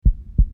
heartbeat.mp3